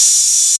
Open Cym 2.wav